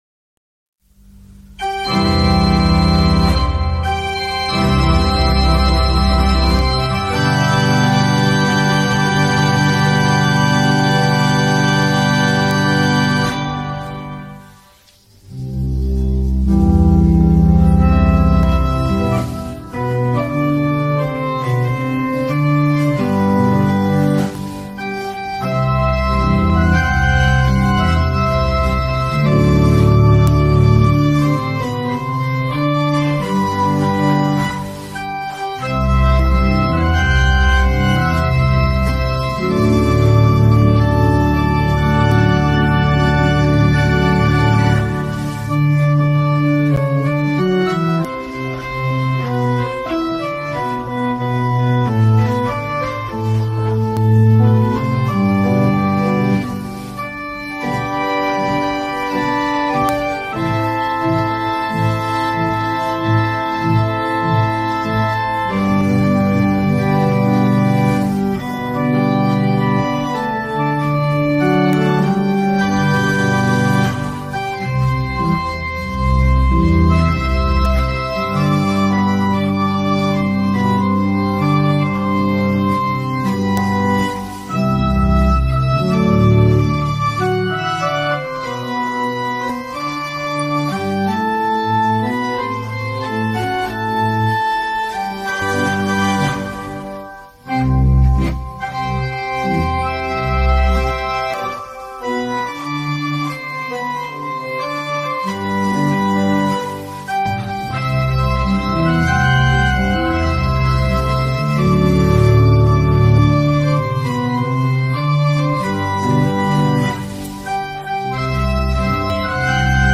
Instrumental-Himno-Escuelas-Parroquiales-Sagrado-Corazon-Olivenza-1.mp3